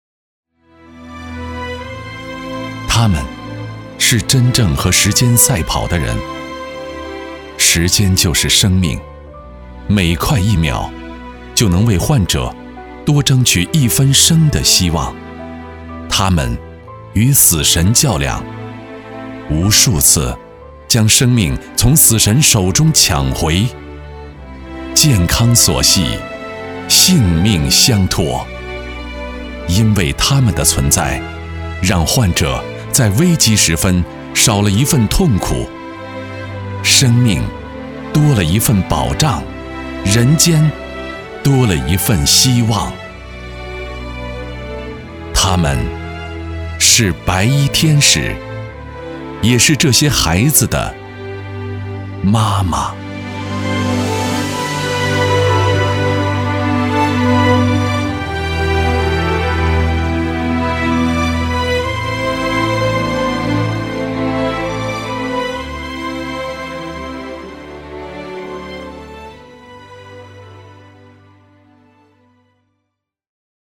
男国258_专题_医院_护士节_温暖.mp3